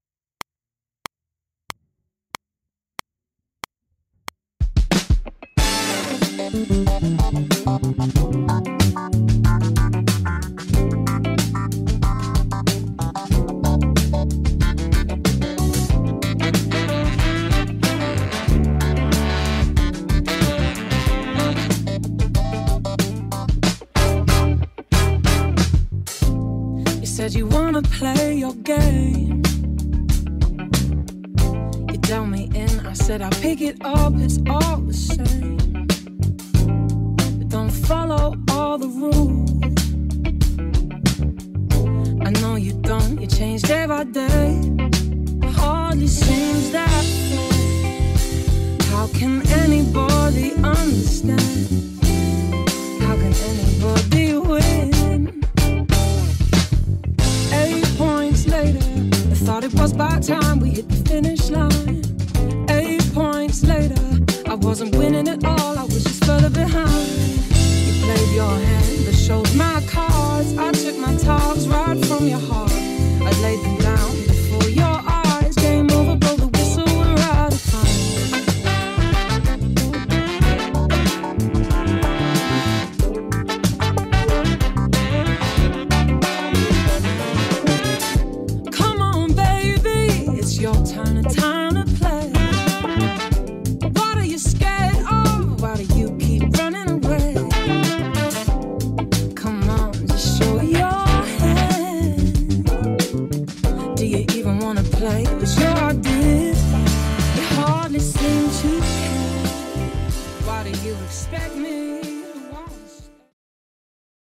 Nahrávky v mixu, struny Thomastik Infeld Jazz ... hlazenky
Mix 1